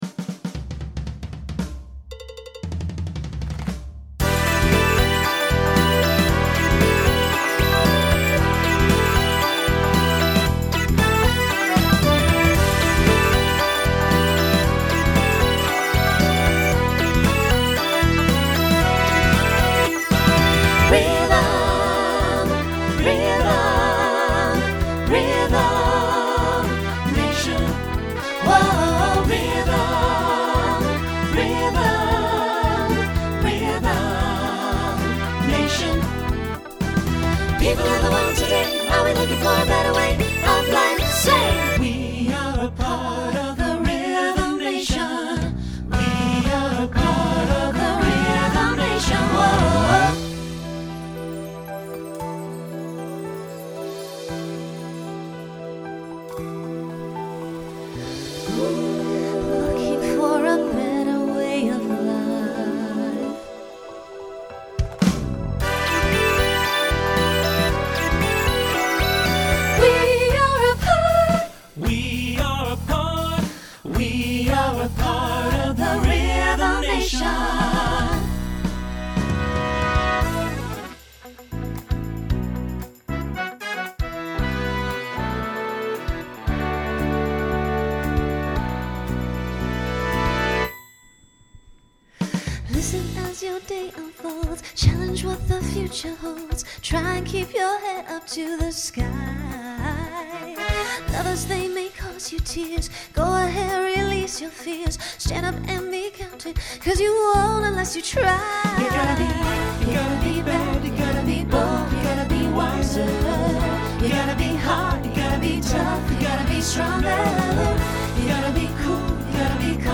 Pop/Dance
Voicing SATB